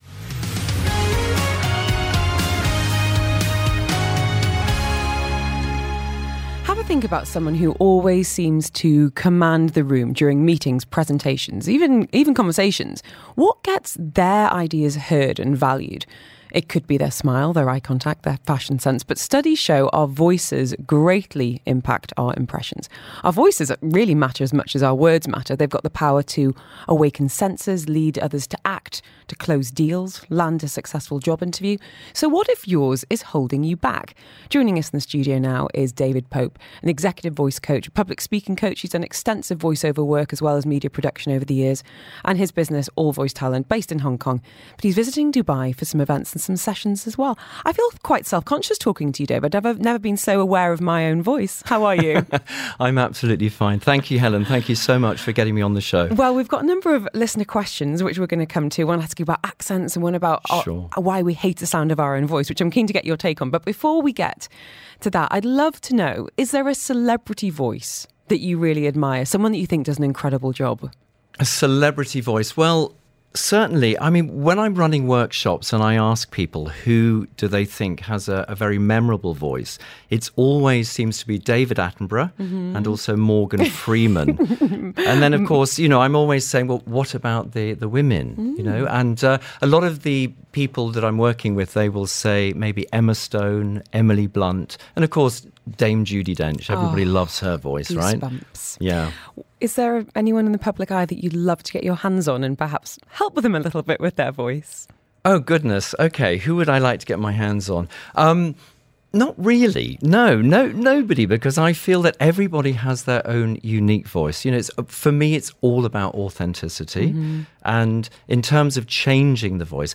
Dubai Radio Interview | All Voice Talent
Dubai-Radio-Interview-June-2023.mp3